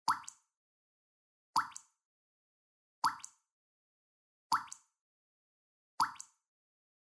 Чистый звук падающих капель воды